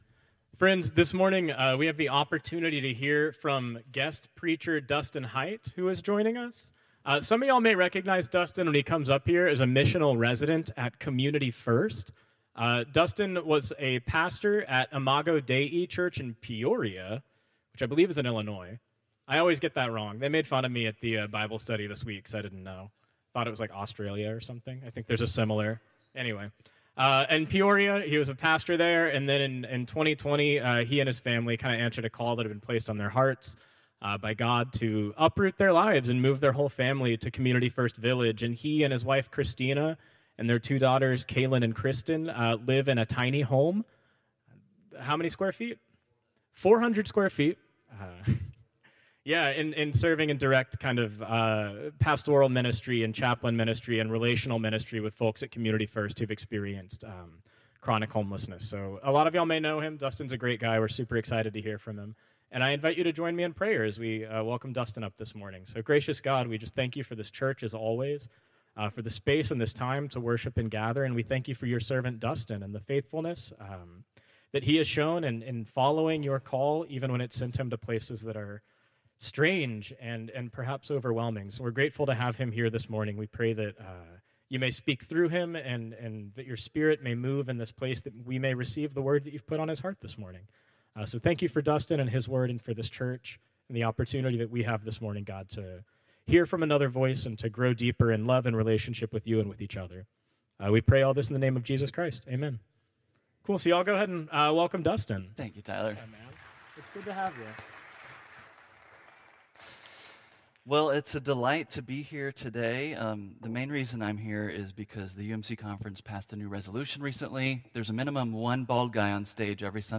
Contemporary Service 6/1/2025